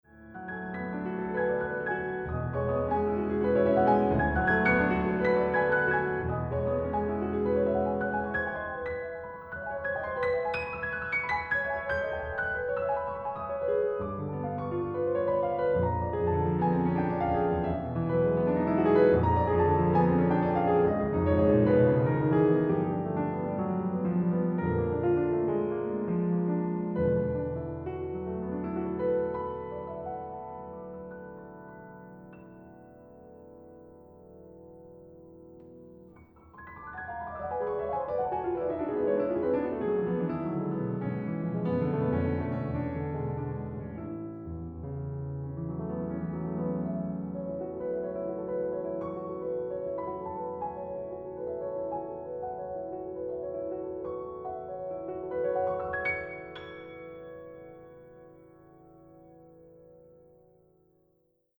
art song
soprano
pianist
Australian, Classical